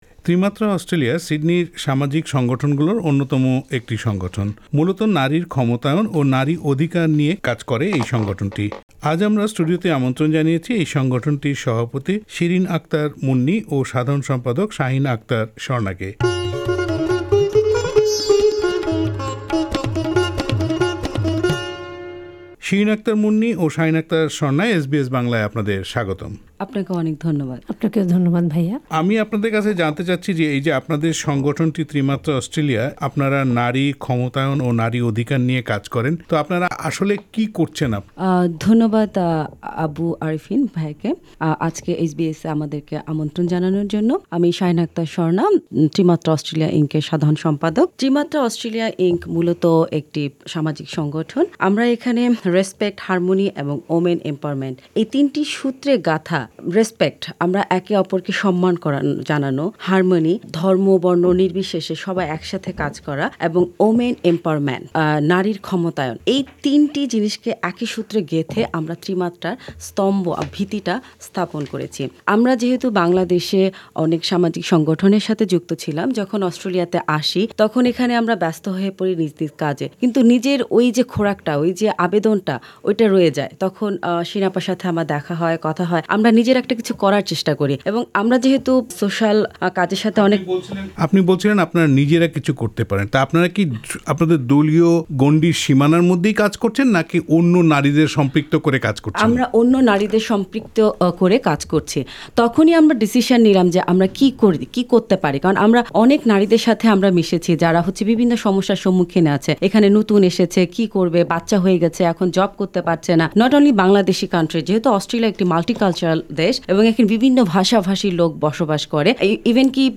এস বি এস বাংলার সঙ্গে আলাপচারিতায়